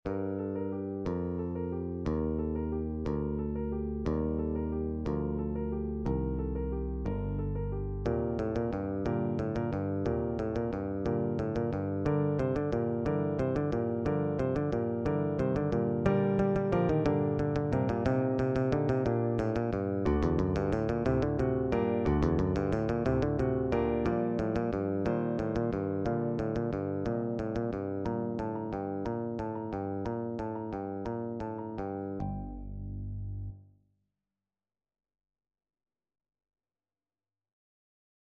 G minor (Sounding Pitch) (View more G minor Music for Bass Guitar )
Presto =c.180 (View more music marked Presto)
3/4 (View more 3/4 Music)
Bass Guitar  (View more Intermediate Bass Guitar Music)
Traditional (View more Traditional Bass Guitar Music)